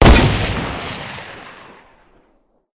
mgun2.wav